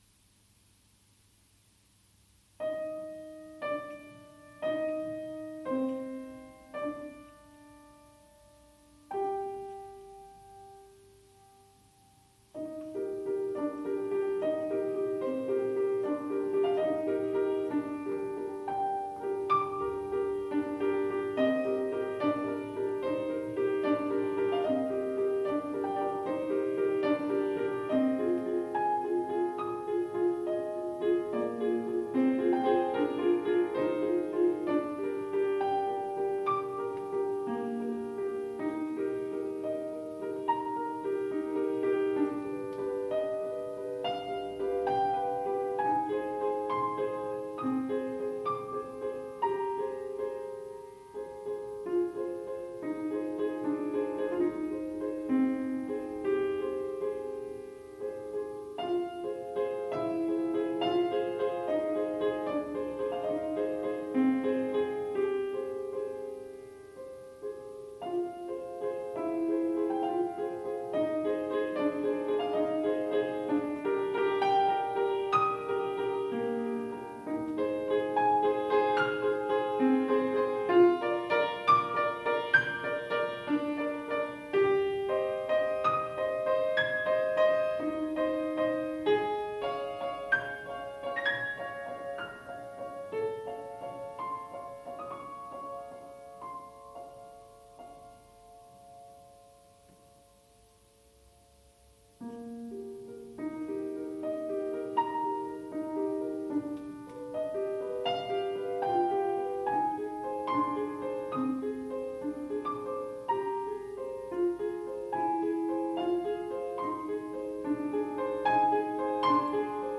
PDF + AUDIO DEMO